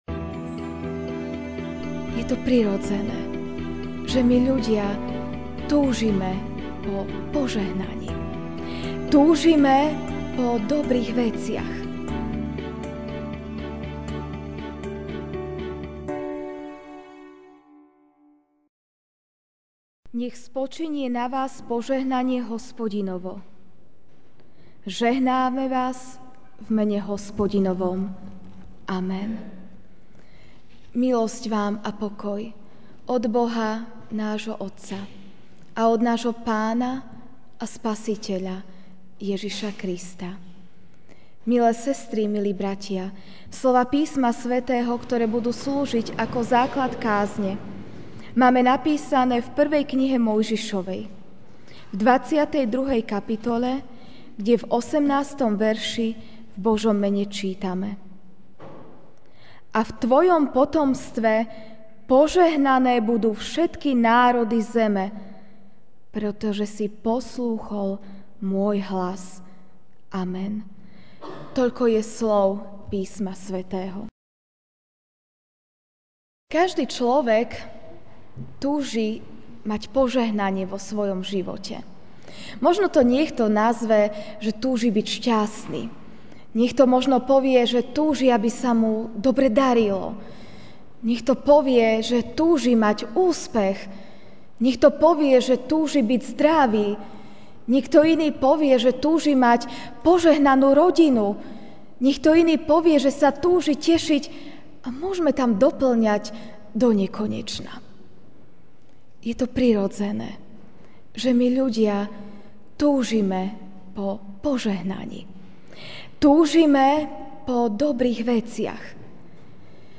júl 10, 2016 Božie požehnanie zachraňuje MP3 SUBSCRIBE on iTunes(Podcast) Notes Sermons in this Series Ranná kázeň: (1.M. 22,18) A v tvojom potomstve požehnané budú všetky národy zeme, pretože si poslúchol môj hlas.